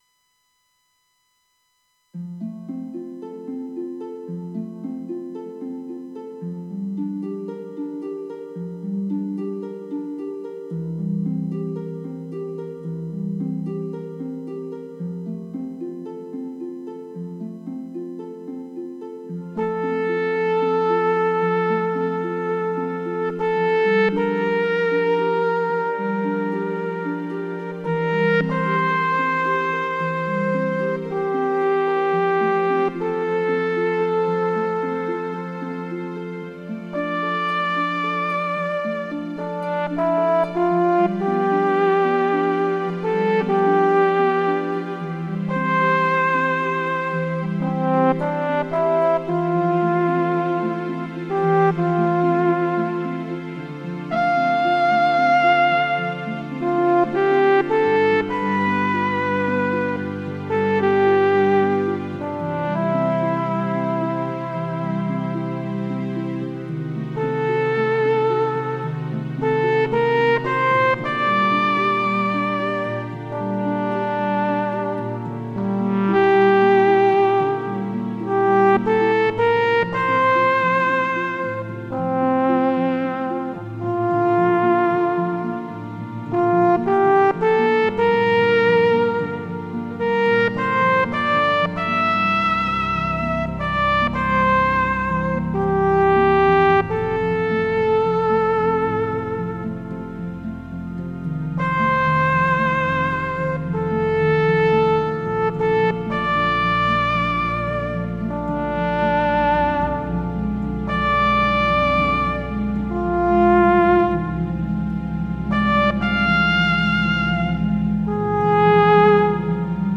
Vous trouverez ici des fichiers audios et vidéos de mes tests avec l’Ewi.
Gounod-Bach-Ave-MariaF-EBalladTrombone.mp3